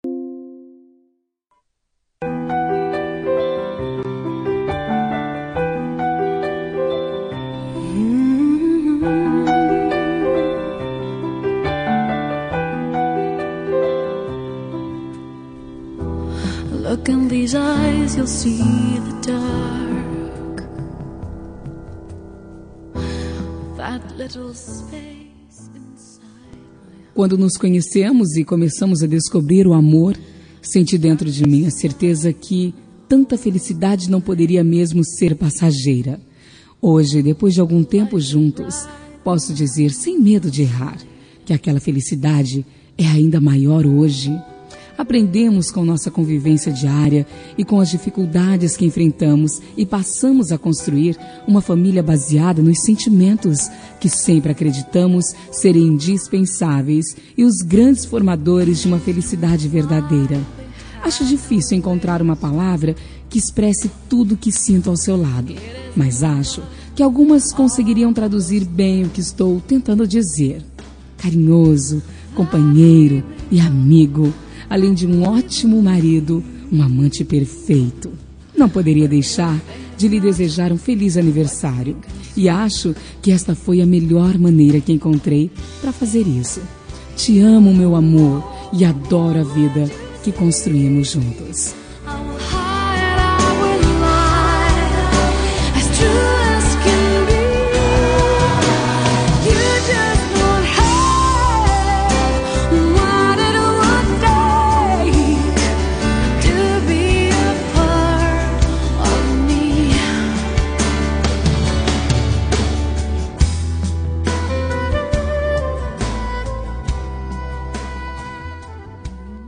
Telemensagem de Aniversário de Marido – Voz Feminina – Cód: 1146